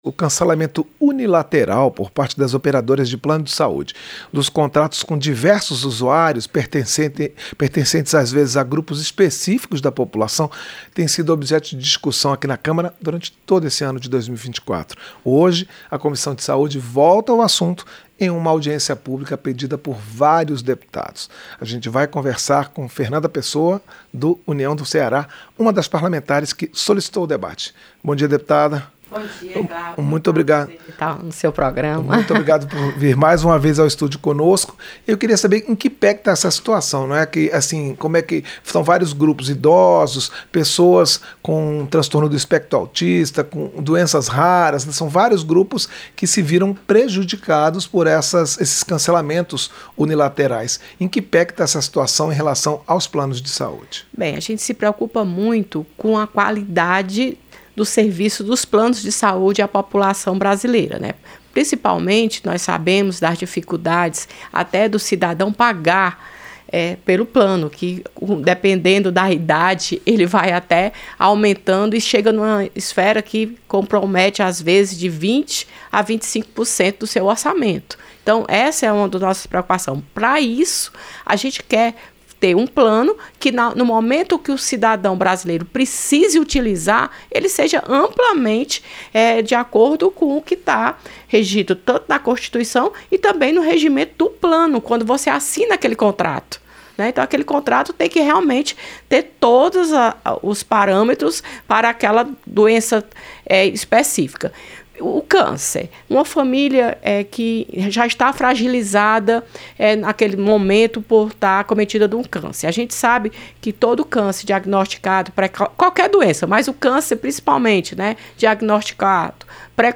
• Entrevista - Dep. Fernanda Pessoa (União-CE)
Programa ao vivo com reportagens, entrevistas sobre temas relacionados à Câmara dos Deputados, e o que vai ser destaque durante a semana.